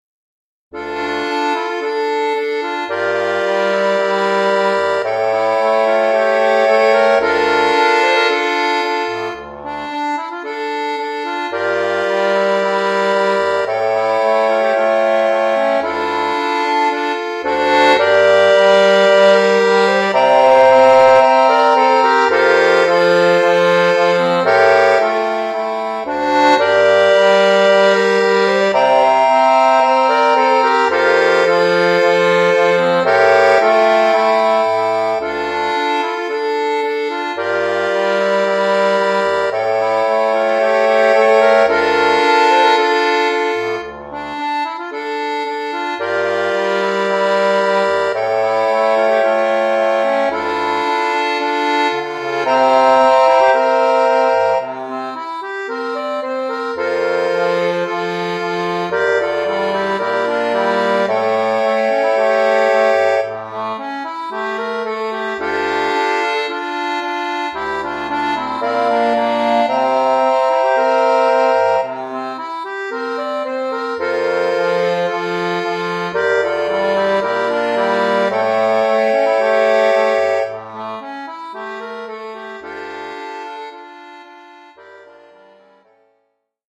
1 titre, accordéon solo : partie d’accordéon
Oeuvre pour accordéon solo.